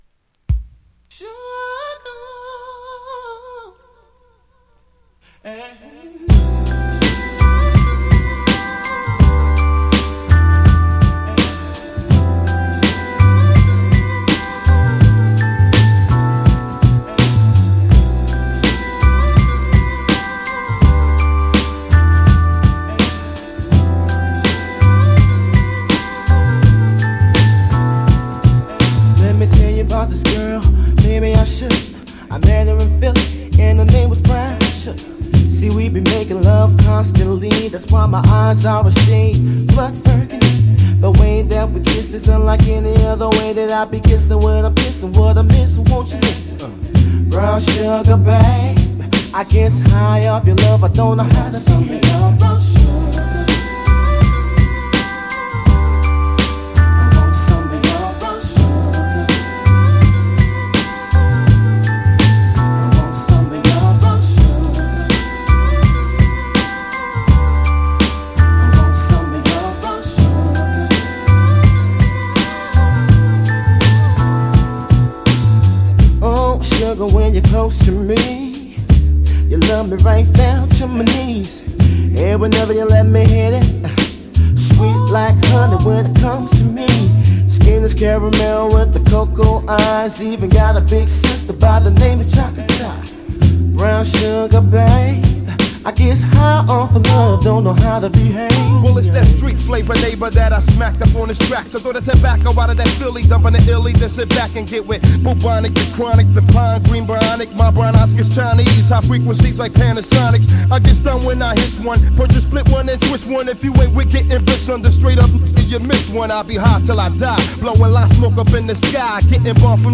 R&B
暖かなエレピの流れに渋いヴォーカルが沁みる90'sメロウ・ソウル… 続き